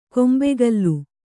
♪ kombegallu